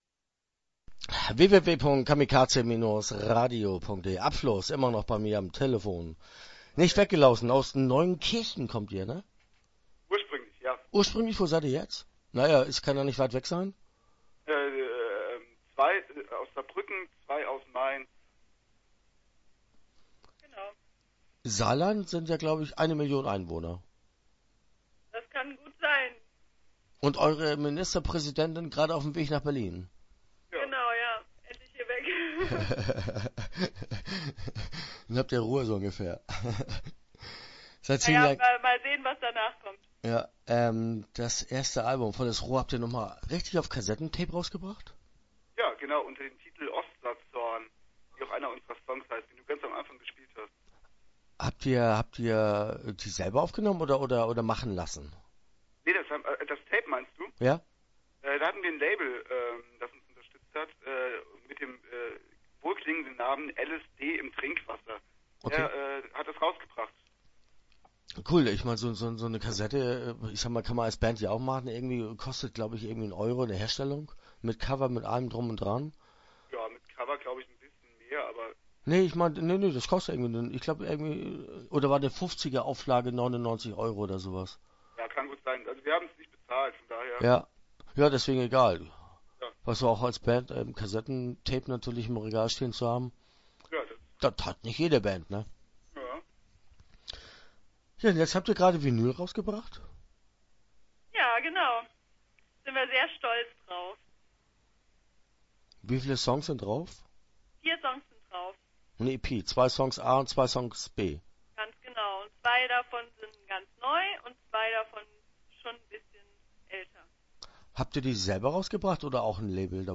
Start » Interviews » Upfluss